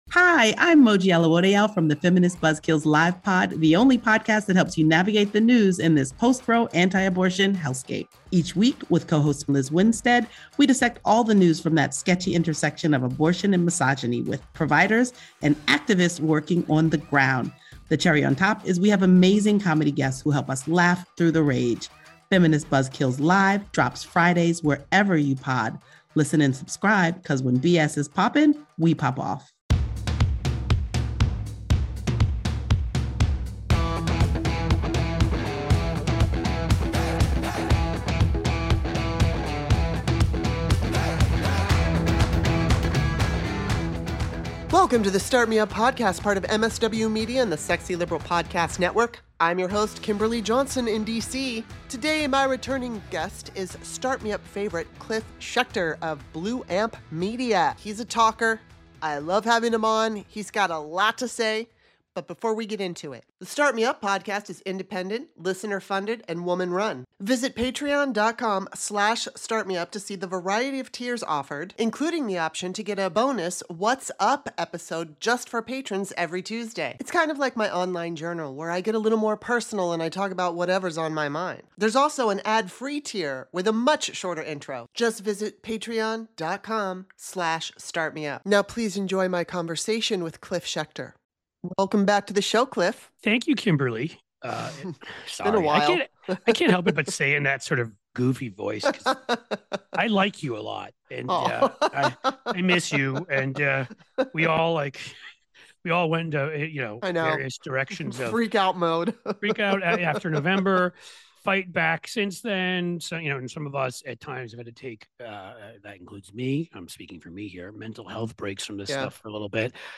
This podcast is for anyone who wants to listen to compelling conversations with a variety of guests about current events and controversial topics.
# News Talk